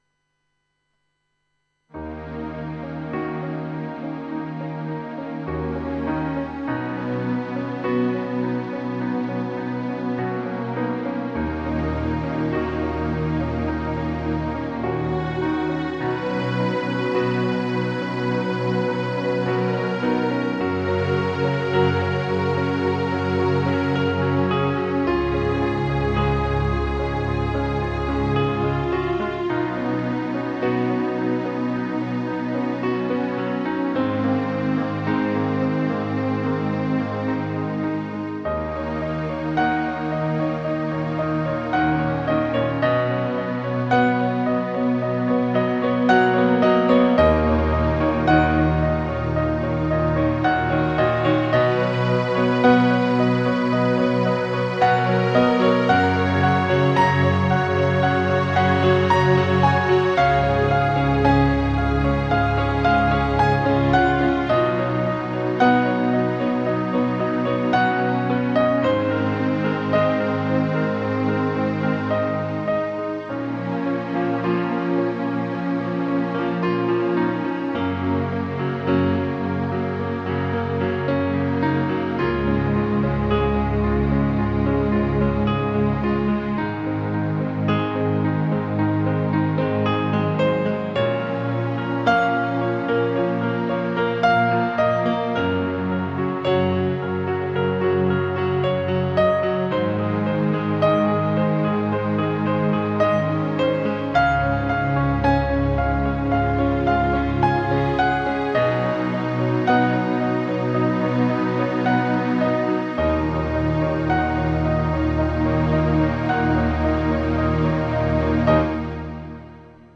Žánr: Indie/Alternativa
Relaxační "easy listening" hudba na piano